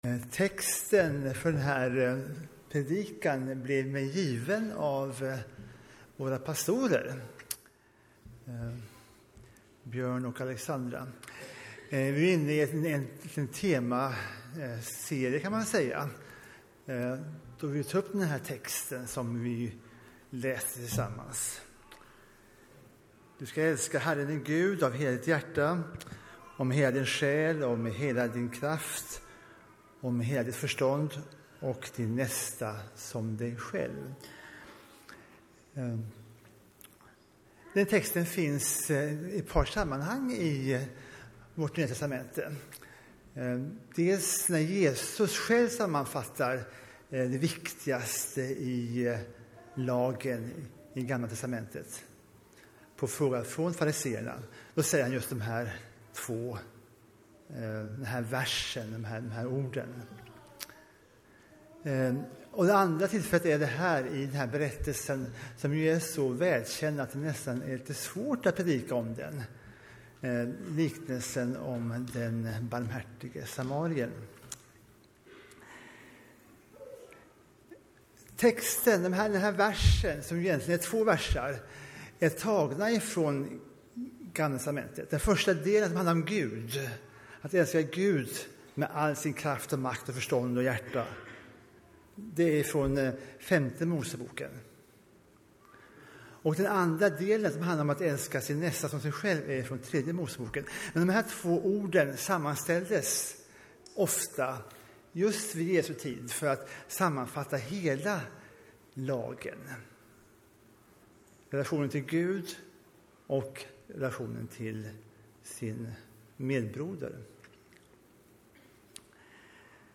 predikar.